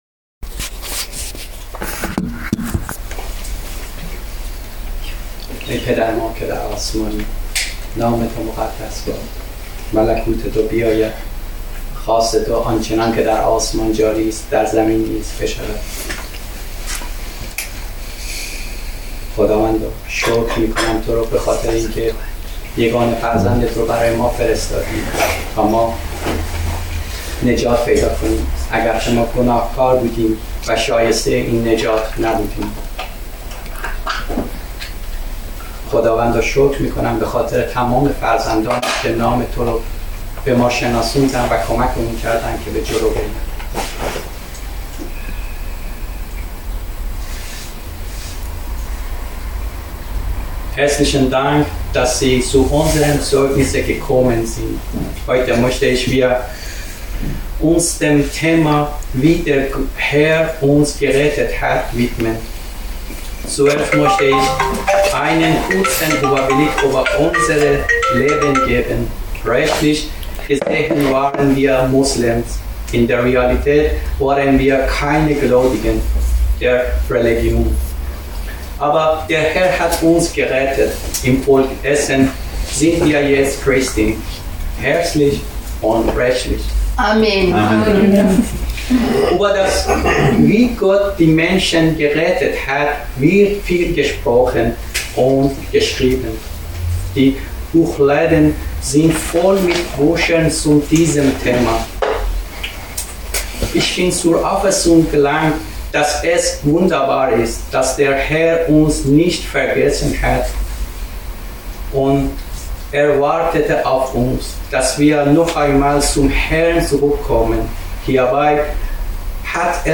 Gastpredigt - Zeugnis